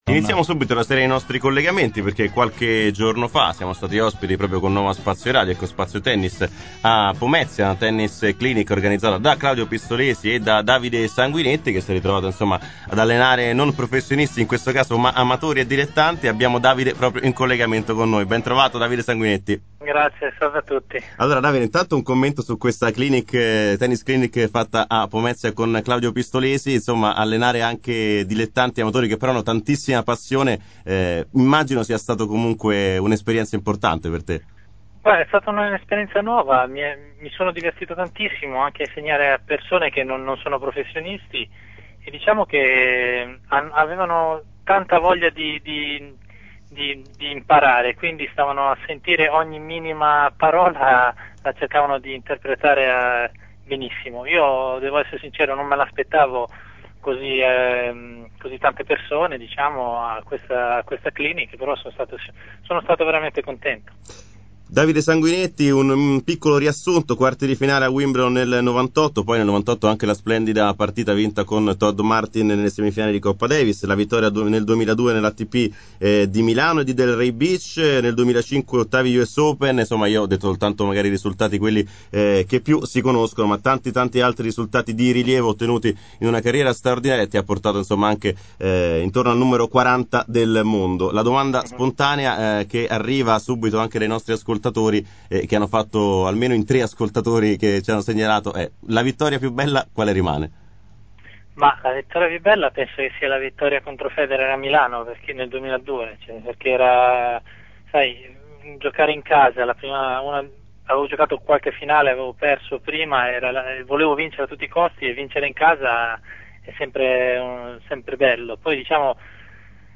Davide Sanguinetti, intervistato
durante la trasmissione Ho Scelto lo Sport su Nuova Spazio Radio. Davide ha parlato del tennis italiano, della sua carriera e soprattutto della sua nuova avventura da allenatore.